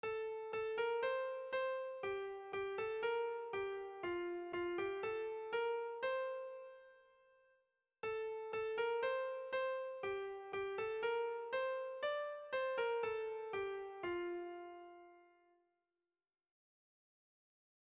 Kontakizunezkoa
A1-A2